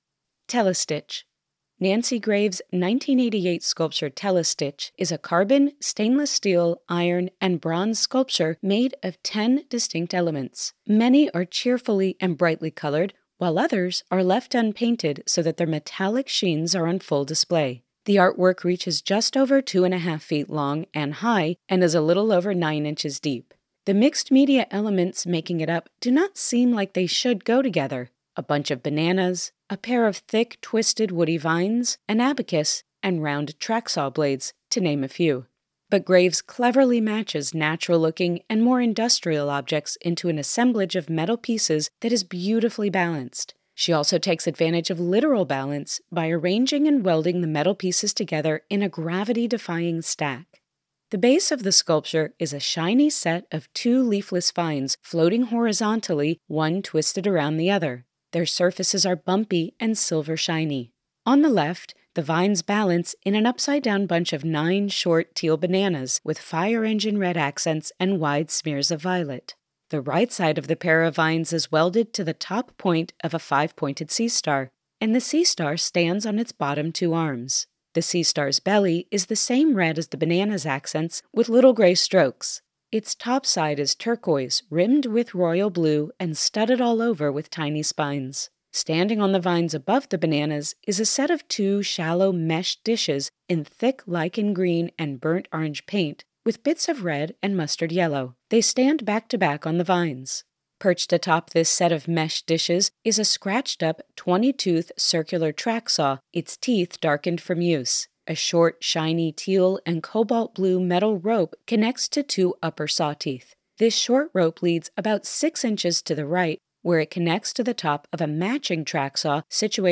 Audio Description (02:59)